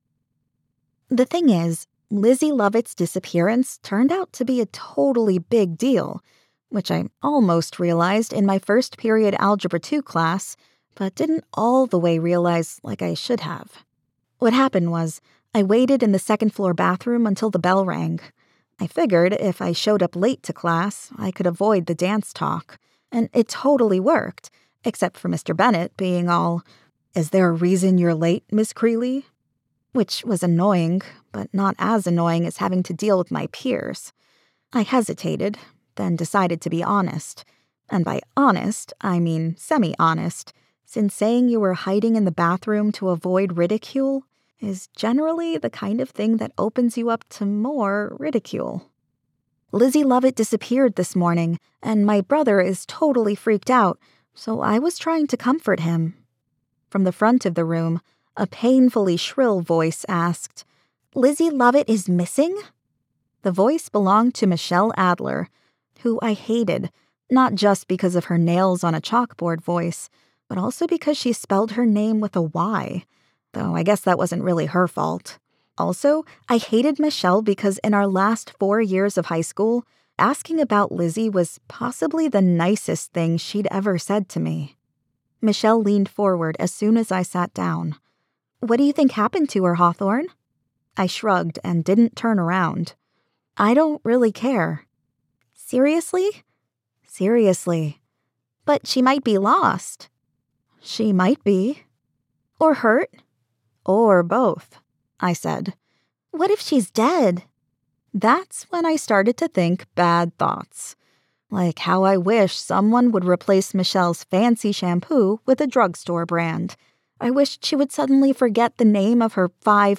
Medical Narration Demo Commercial Demo YA Mystery 1st POV Dialogue RomCom 1st POV F/M Middle Grade 3rd POV F/F Mystery 3rd POV Male Medical Nonfiction (Study guide) Thriller 1st POV Horror 3rd POV F/F
New-YA-Demo.mp3